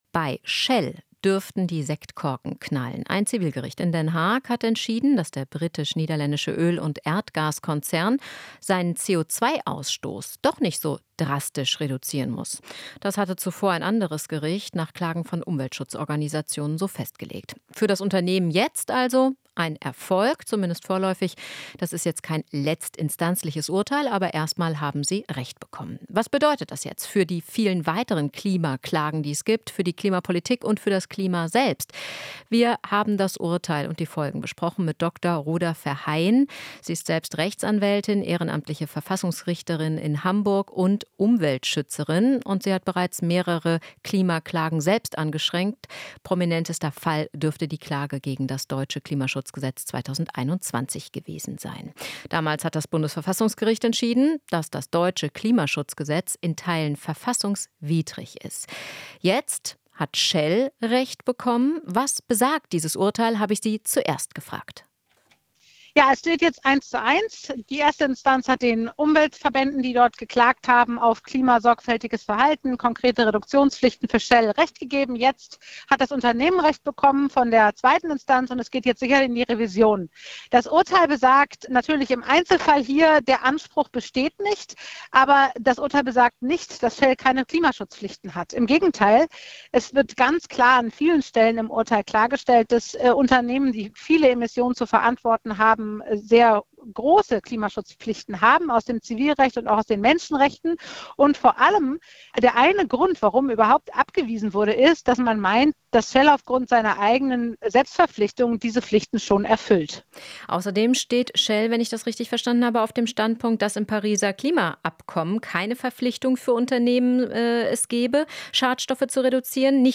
Interview - Juristin: Urteil befreit Shell nicht von Klimaschutzpflichten